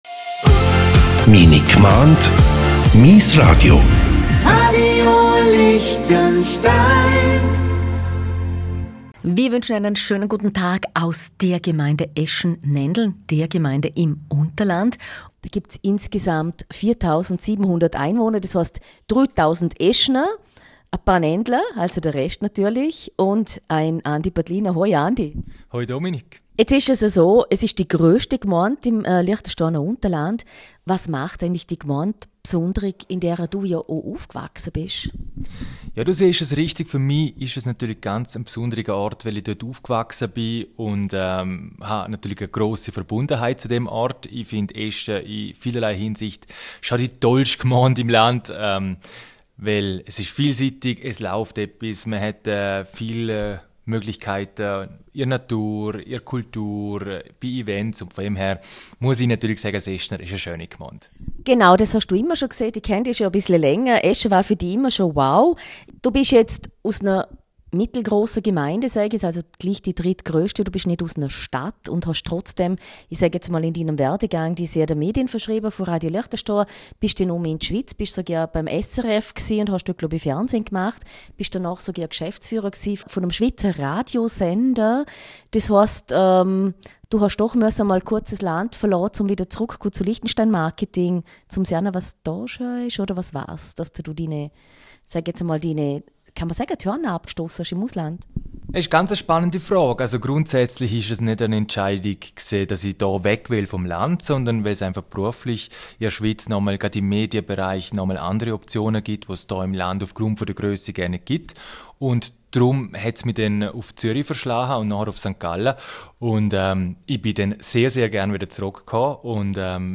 Gespräch Herunterladen